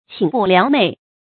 寝不聊寐 qǐn bù liáo mèi 成语解释 亦作“寝不成寐”。